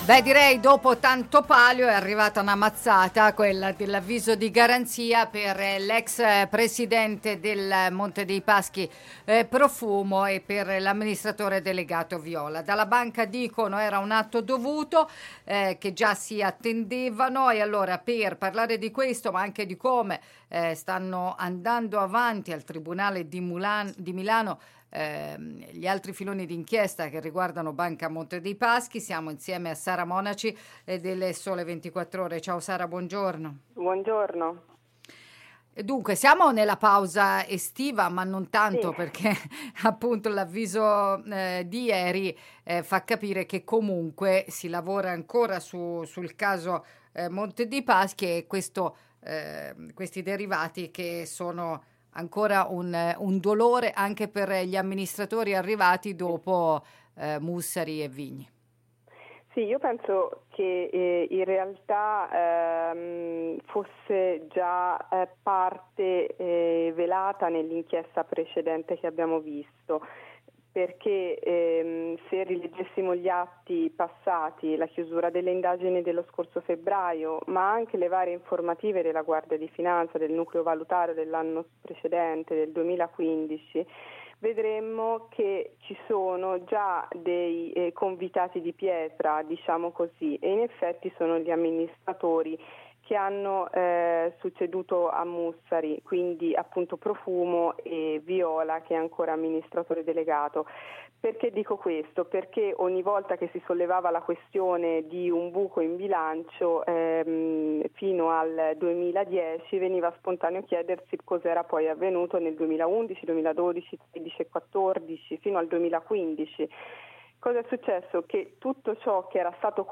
L’aver continuato a mettere a bilancio i derivati Alexandria e Santorini ha portato all’avviso di garanzia per l’ex presidente Profumo e per l’attuale ad Viola di Banca Mps, con l’accusa di falso in bilancio e manipolazione di mercato. Lo ha spiegato ai nostri microfoni